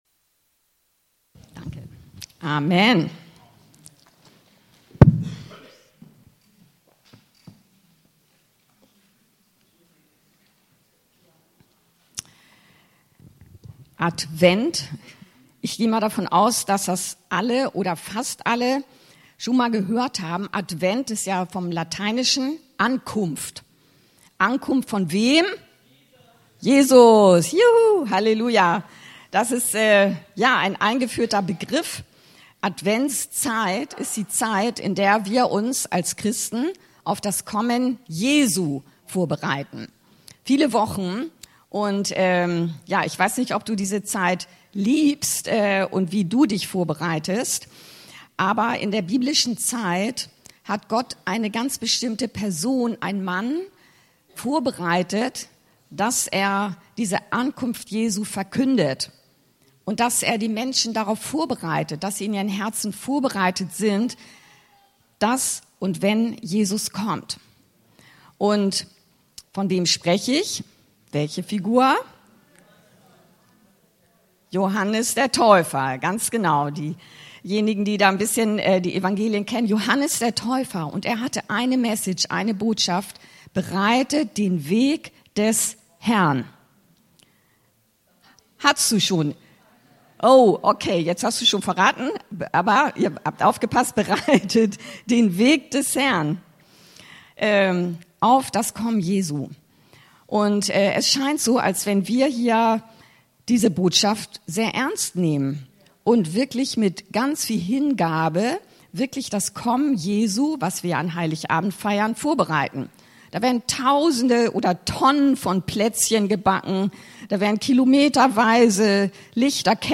Anskar-Kirche Hamburg- Predigten Podcast